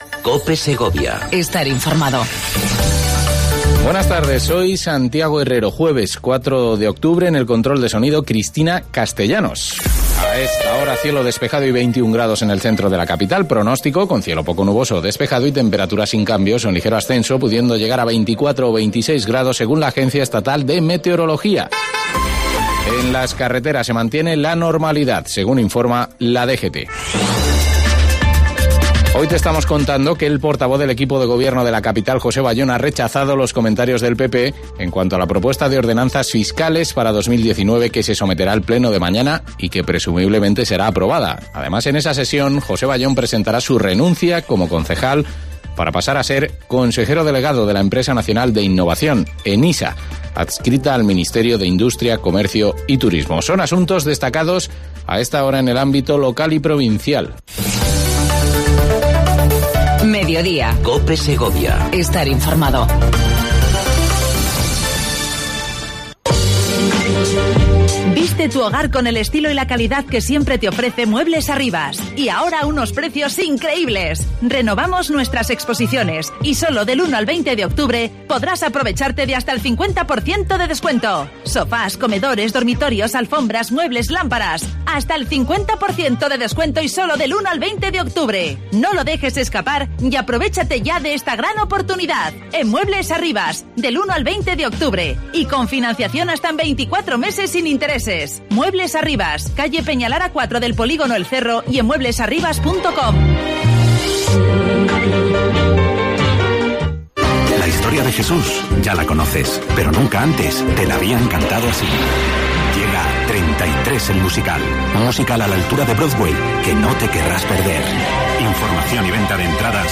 AUDIO: Entrevista a Cosme Aranguren portavoz del grupo municipal UPYD centrados en Segovia